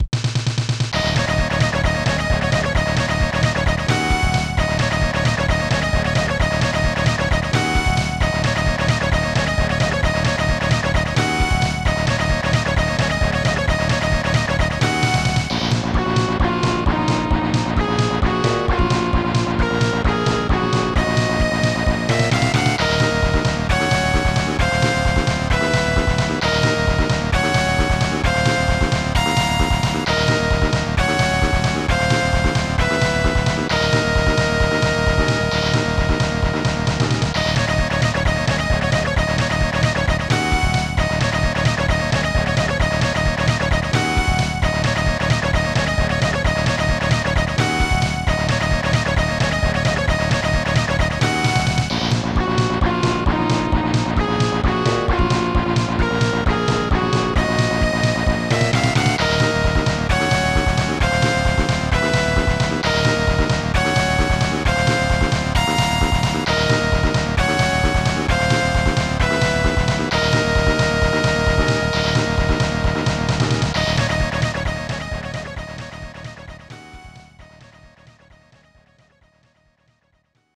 • Качество: 244, Stereo
электронная музыка
без слов
забавная мелодия